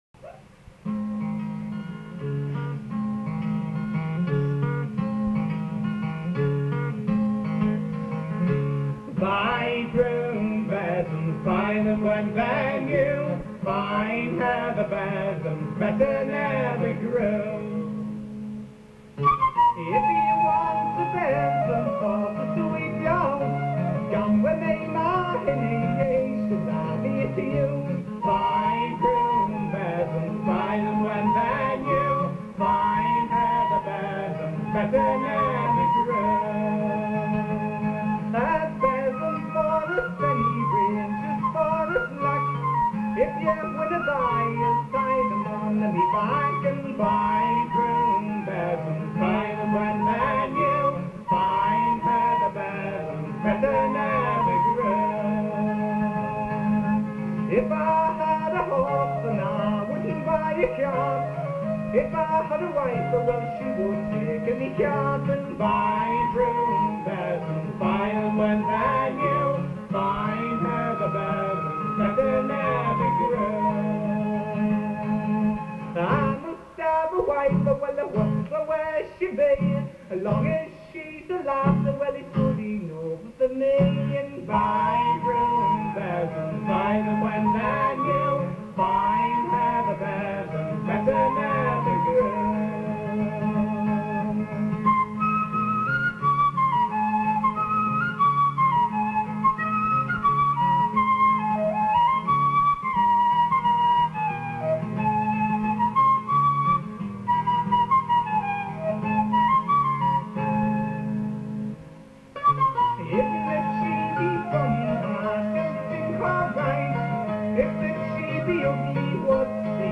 The recording quality was probably never very good and has inevitably deteriorated over the years but still gives an idea of the nature of the material and the approach to its performance.
Flute
Mandolin
Lead Vocals
Guitar